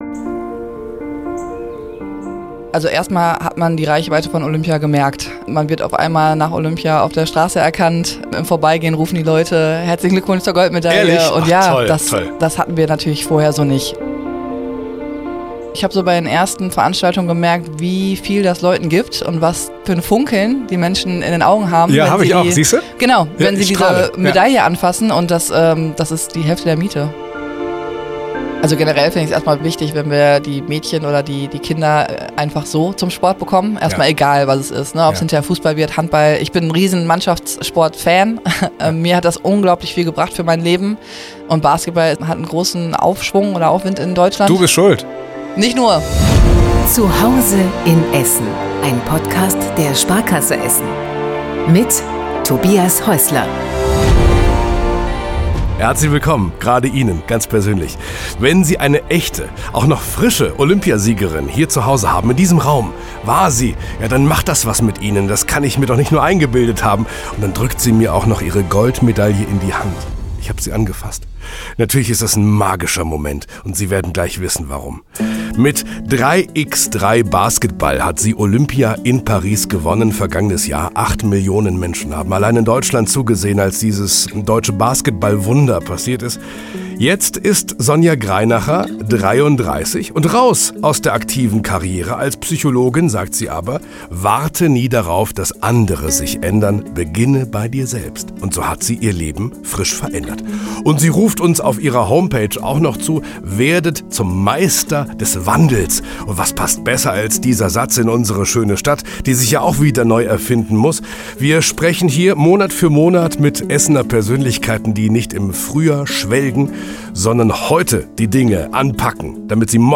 Wir diskutieren, wie Teamgeist im Sport und im Leben wirkt, was gute Führung ausmacht und warum Mädchen im Essener Sport endlich wieder ihre Bühne brauchen. Ein ehrliches, motivierendes Gespräch über Erfolge, Verantwortung und den Mut, immer wieder neu zu starten.